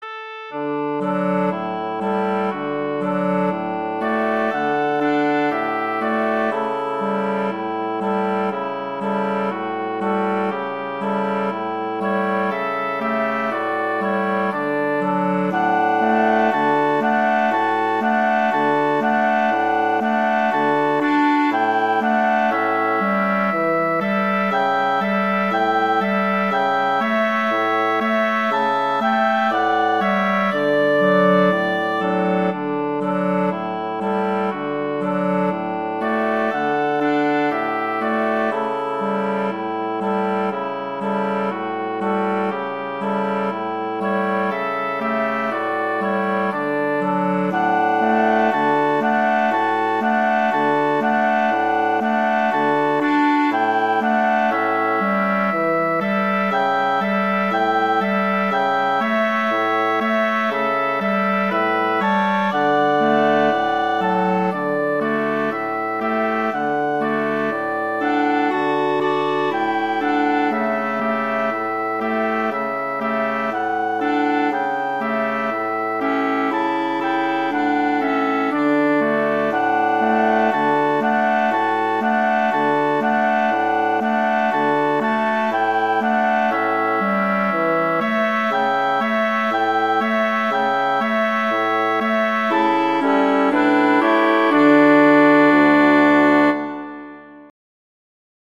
Instrumentation: wind quartet
arrangements for wind quartet
♩=60-120 BPM
flute:
oboe:
clarinet:
bassoon: